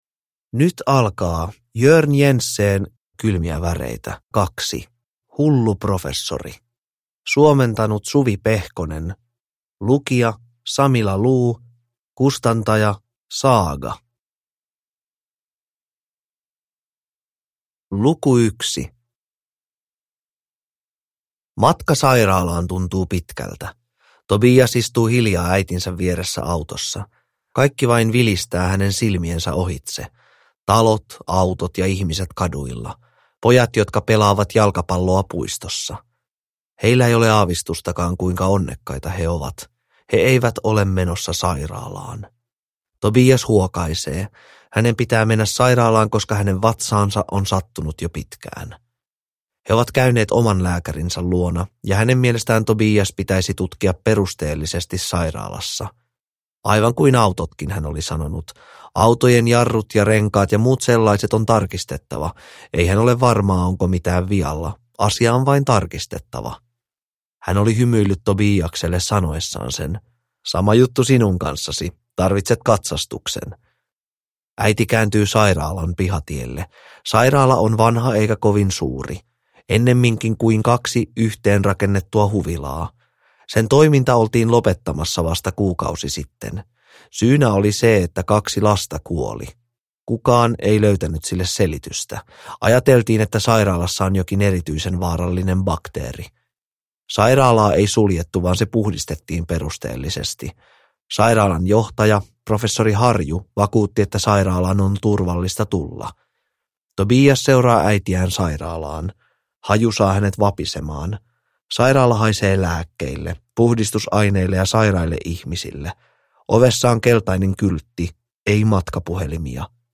Kylmiä väreitä 2: Hullu professori (ljudbok) av Jørn Jensen